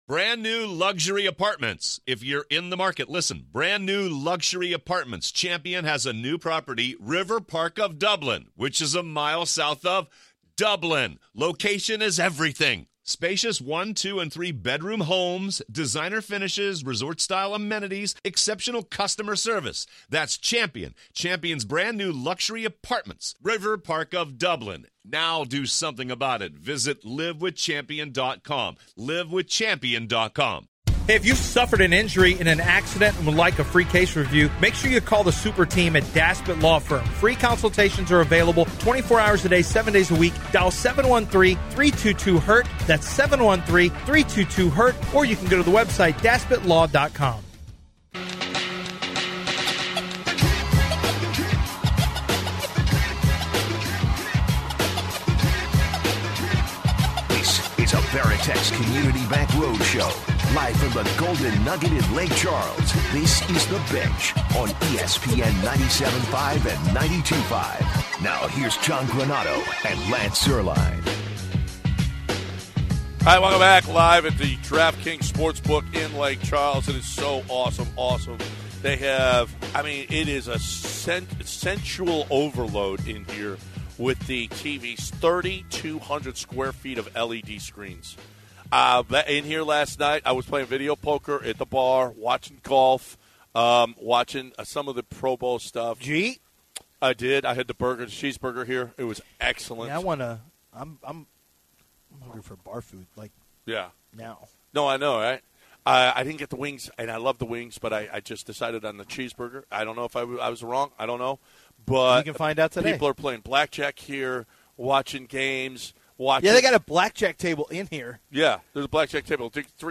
they transition to DeAngelo Russell and the NBA trades and draft picks and let a few callers share their opinions on the picks.